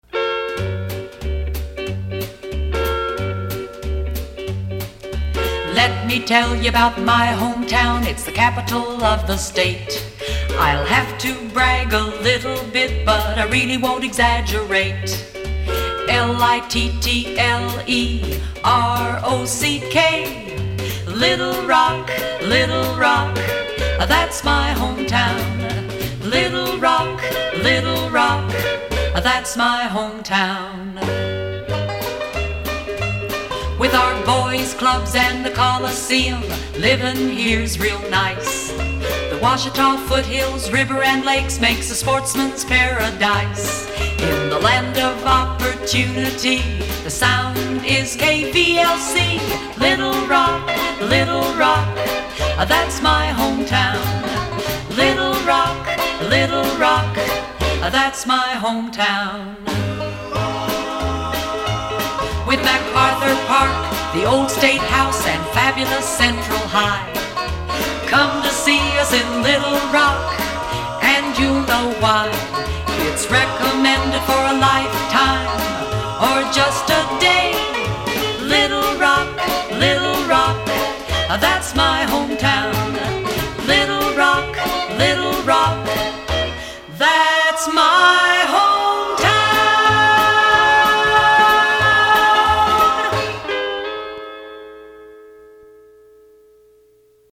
NOTE: These jingle samples are from my private collection.